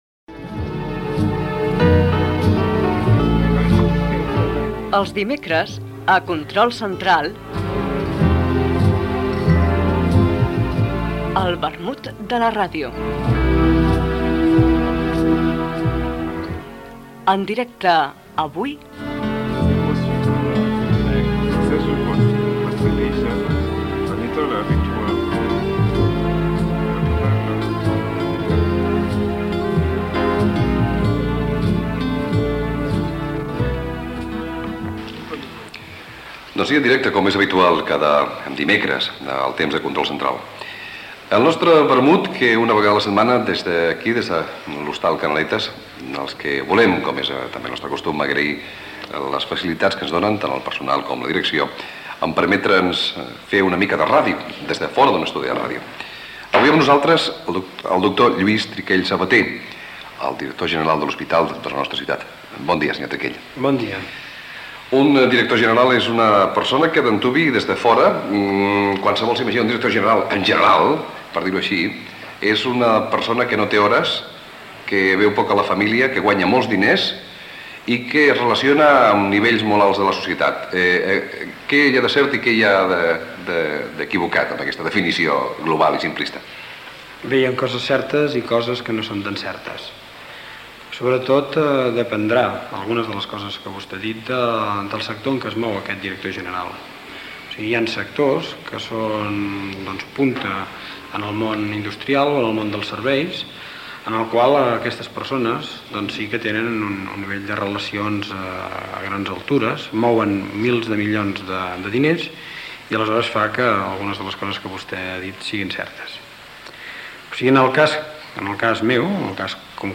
Espai fet en directe des de l'Hostal Canaletas d'Igualda.